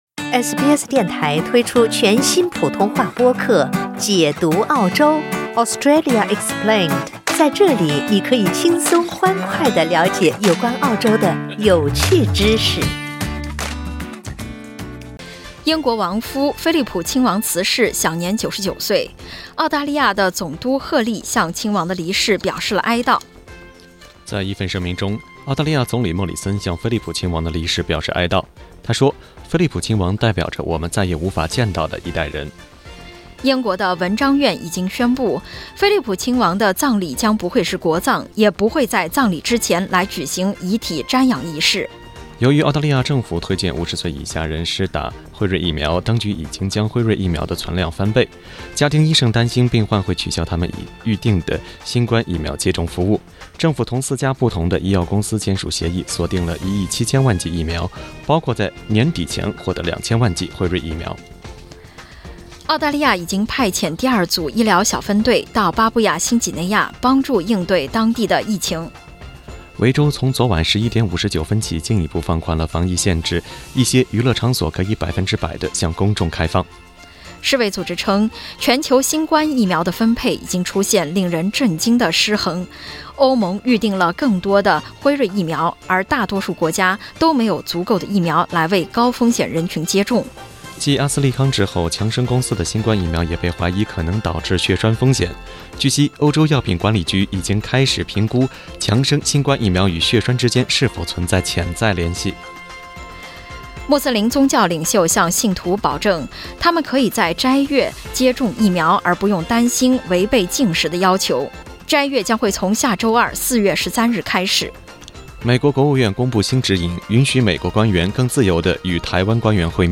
SBS早新聞（4月10日）